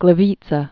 (glĭ-vētsə, glē-vētsĕ)